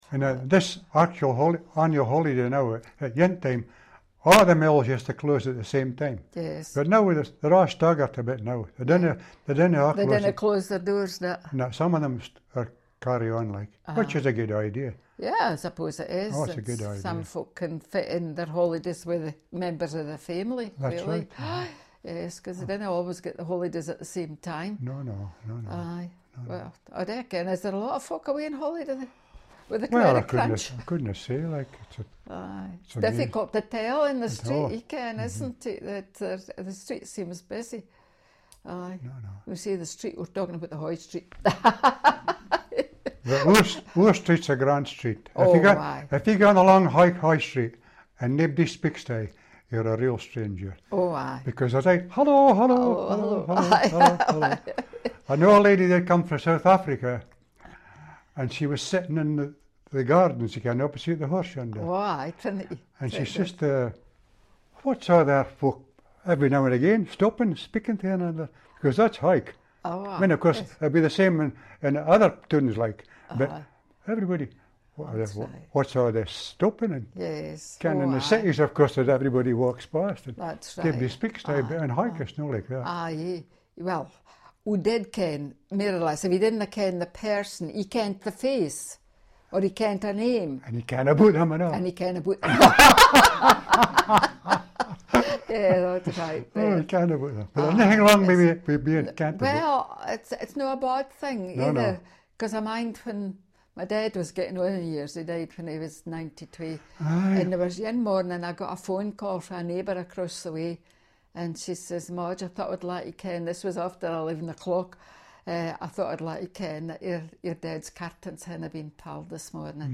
The sound files provided here are recordings made in Hawick and Newcastle upon Tyne in 2009 as part of the NESPS project. They are taken from the free conversation which was recorded as part of the fieldwork session. Informants were recorded in aged-matched pairs, as described above.
bullet Hawick older speakers 1 [
hawick-older-1.mp3